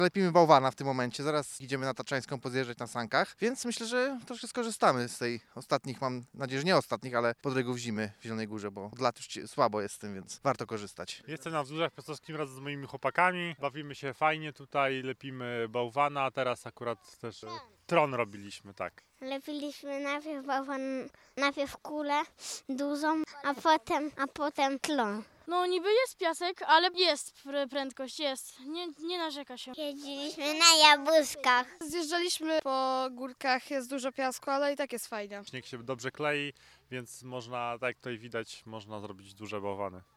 Zapytaliśmy mieszkańców, co robią w taką pogodę na Górce Tatrzańskiej i jak się bawią?: